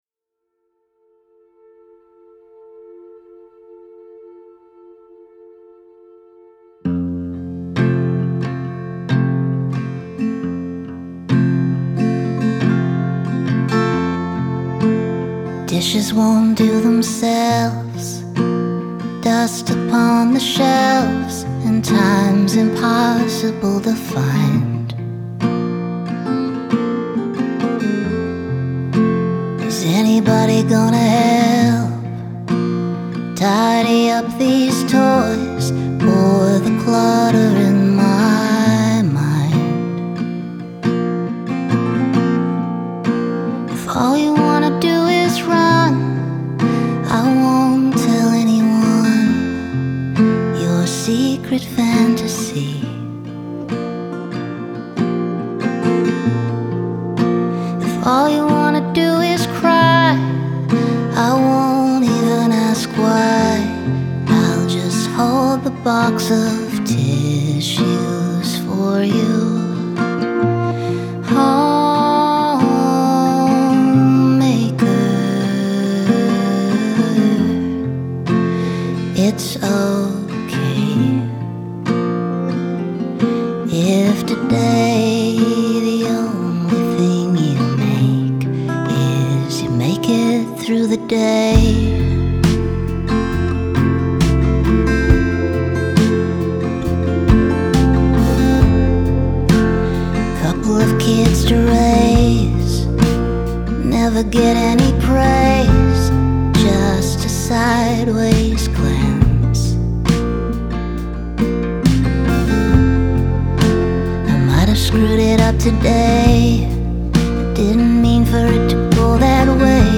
Genre : Country